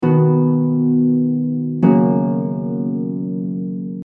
• # Traditional Irish melody
• prestissimo
It’s a nice little stinger, easily recognizable as one of the many ways of singing Amen to end a hymn.
In this case, it’s the key of F Major, which has one flat, the B.
The Amen uses Harp.